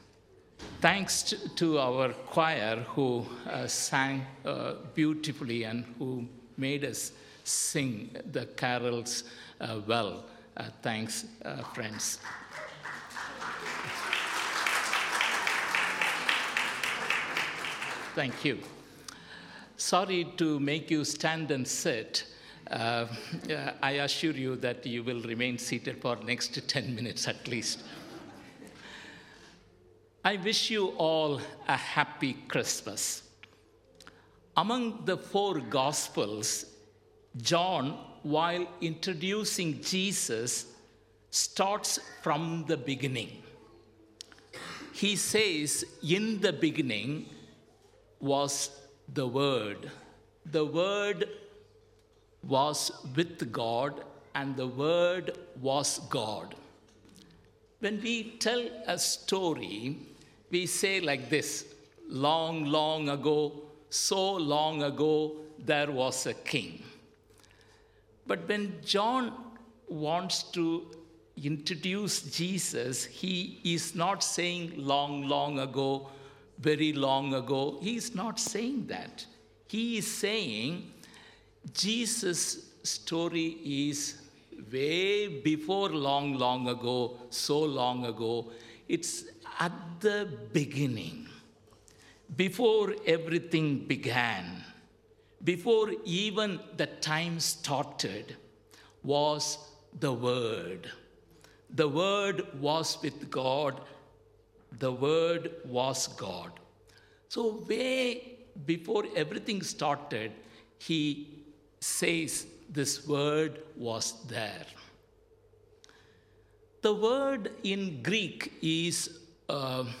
Sermon- December 24th, 2025